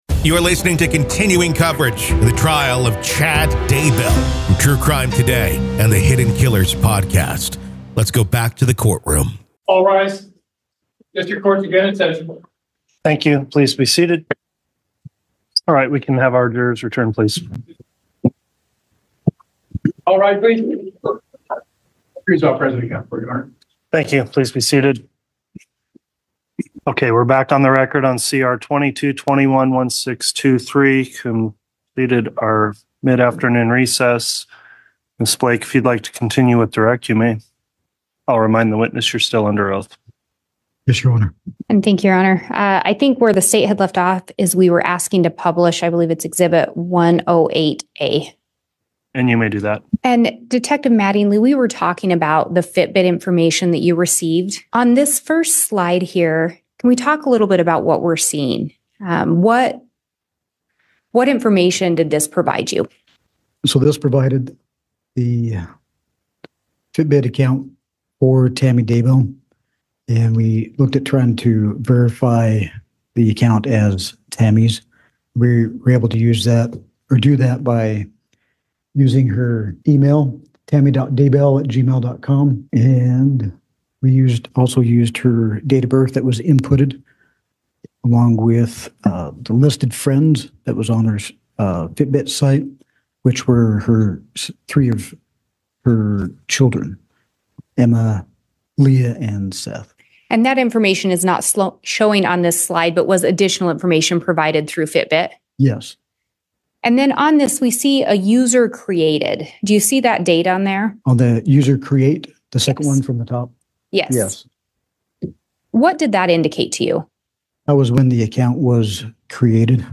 Full Courtroom Coverage